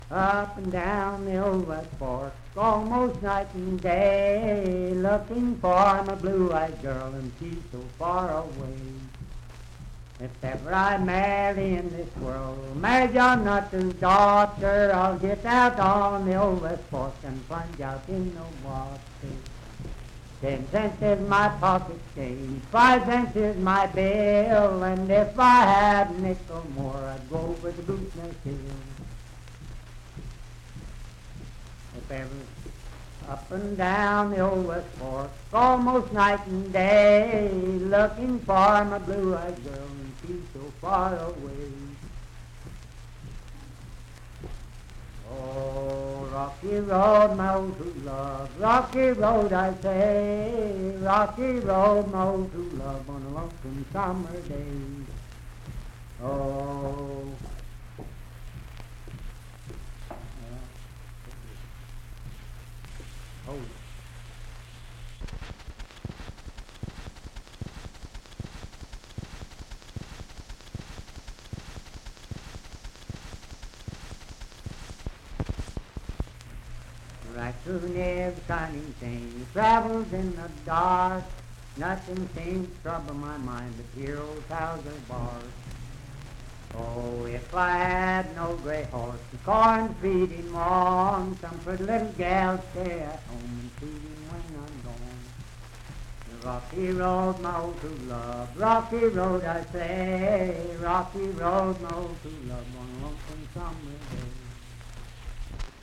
Unaccompanied vocal music and folktales
Verse-refrain 8(4).
Voice (sung)
Parkersburg (W. Va.), Wood County (W. Va.)